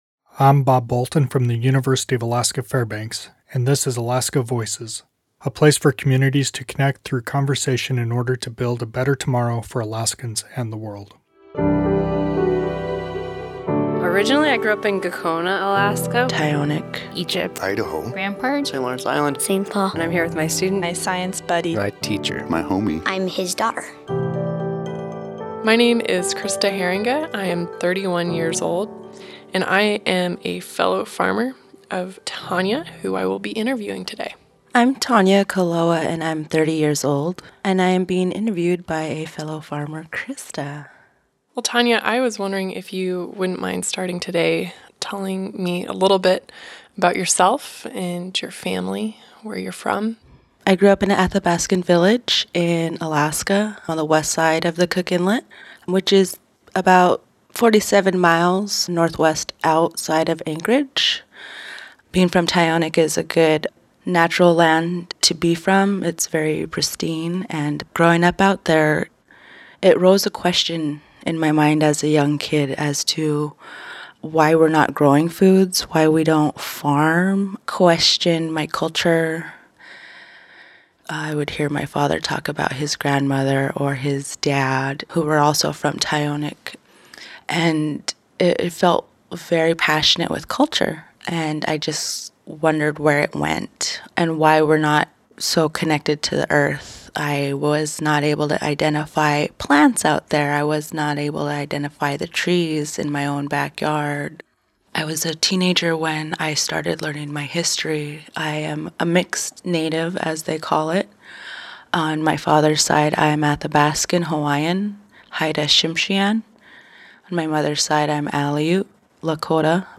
This interview was recorded in collaboration with StoryCorps.